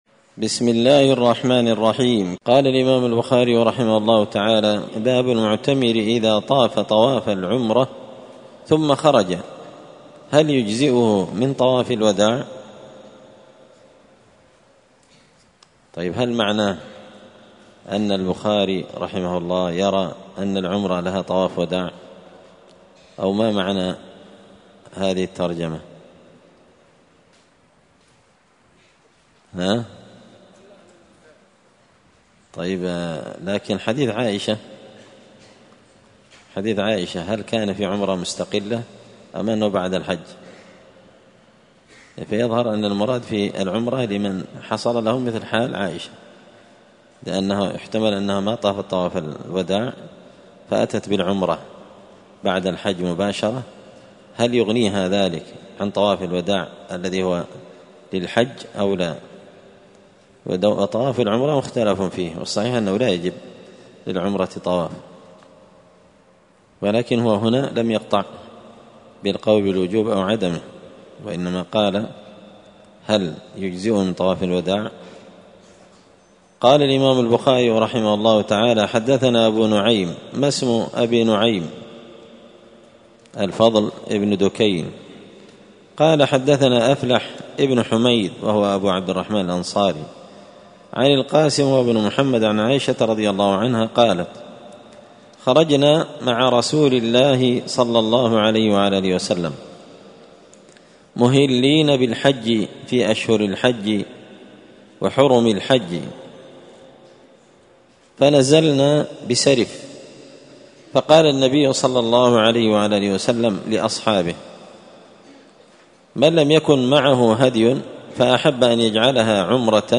الأثنين 6 محرم 1445 هــــ | الدروس، شرح صحيح البخاري، كتاب العمرة | شارك بتعليقك | 9 المشاهدات